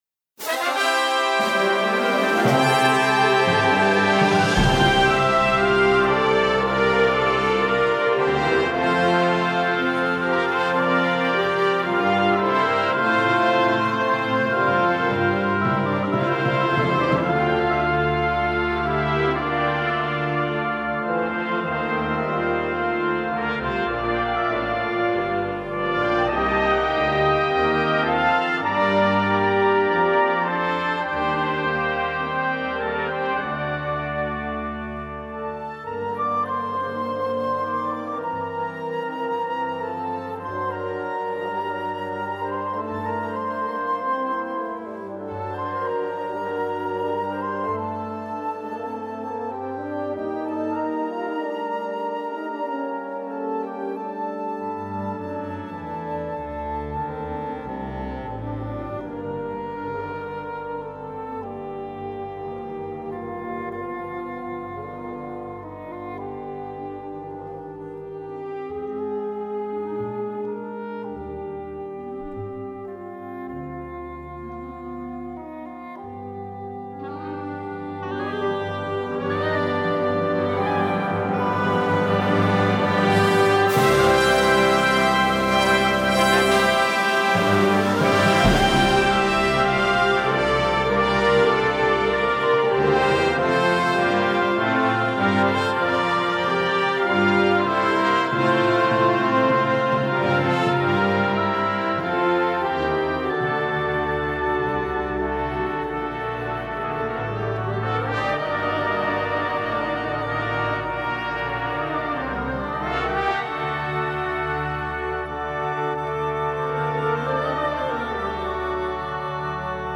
Gattung: Konzertante Blasmusik
23 x 30,5 cm Besetzung: Blasorchester Zu hören auf